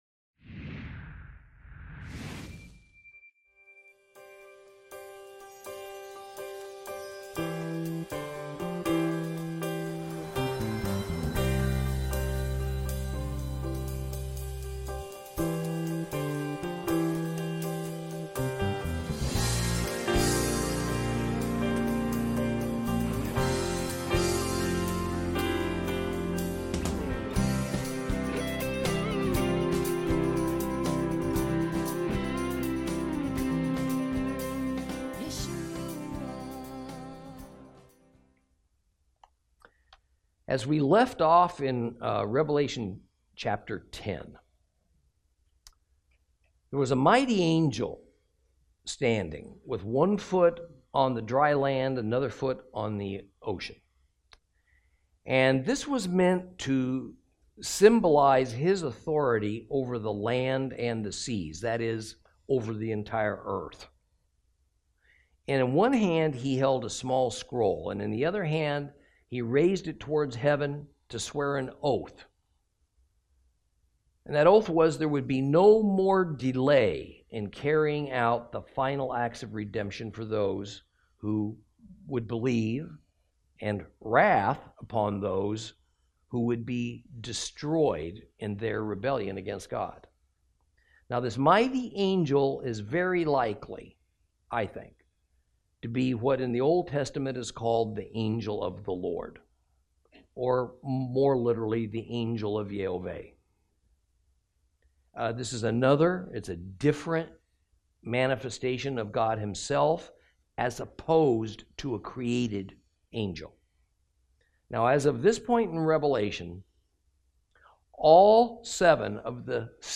Lesson 21 – Revelation 10 & 11